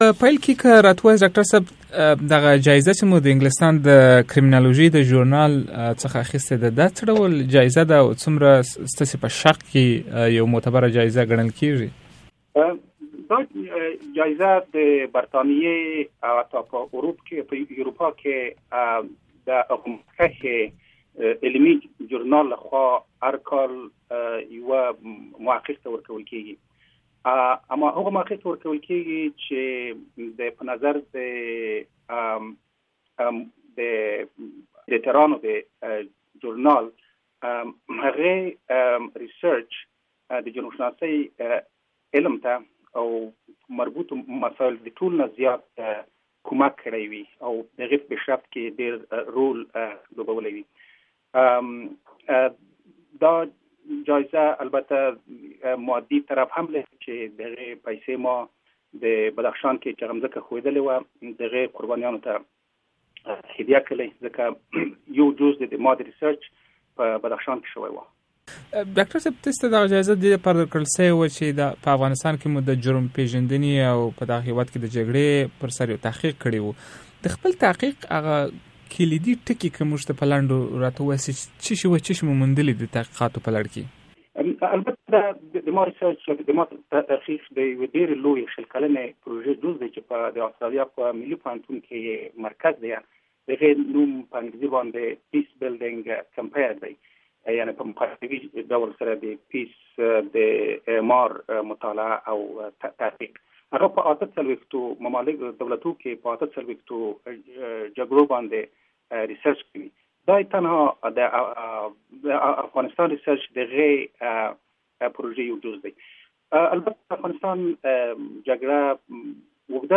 Pashto interview